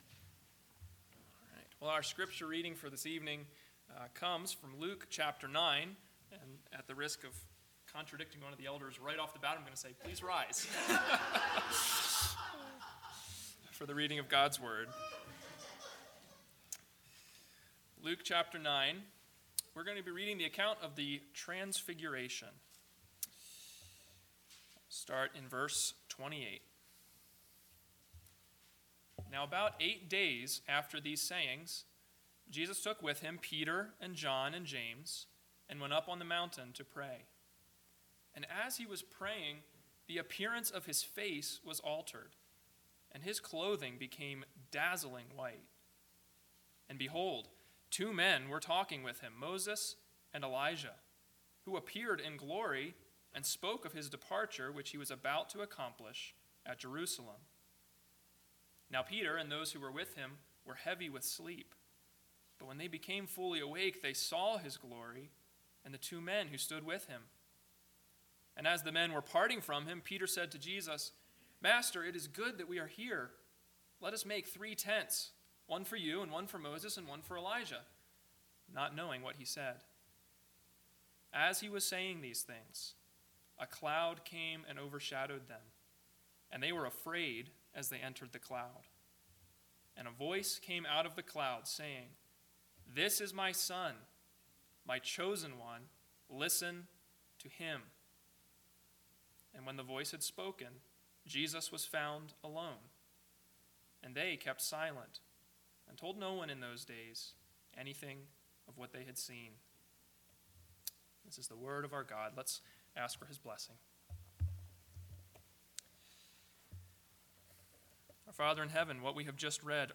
PM Sermon – 05/02/2021 – Luke 9:28-36 – Listen to Him!